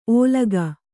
♪ ōlaga